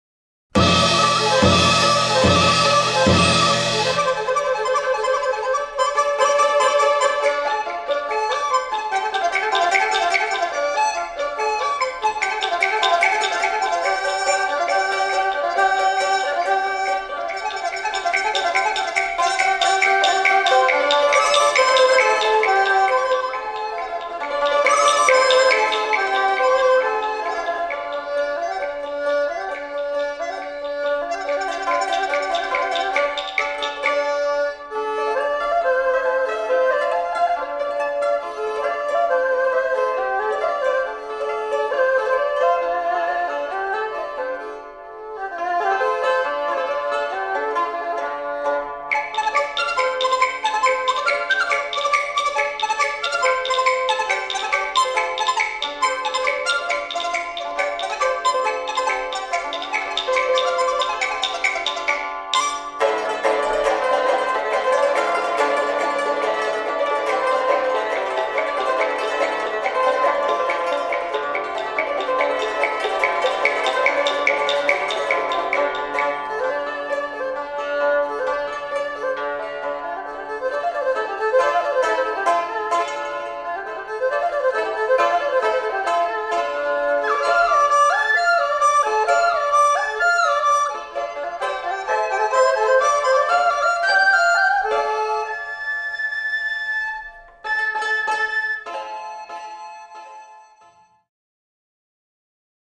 Carreras (música tradicional china)